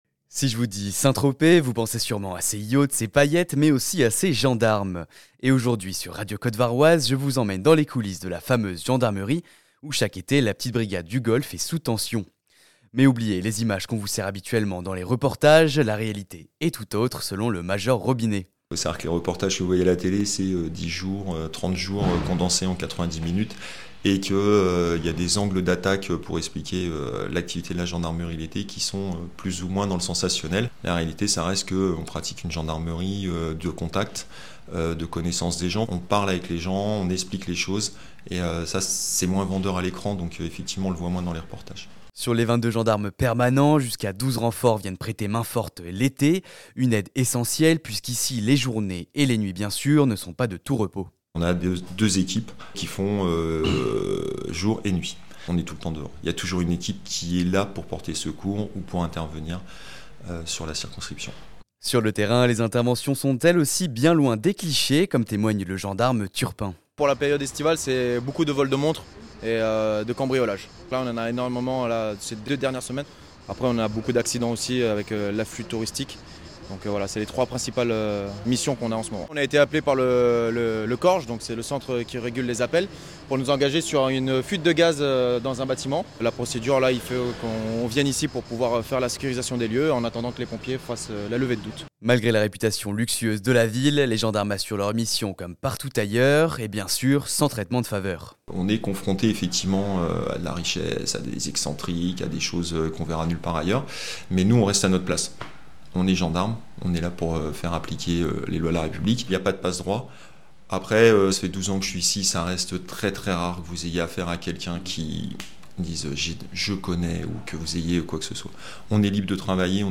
REPORTAGE BRIGADE DE GENDARMERIE DE SAINT TROPEZ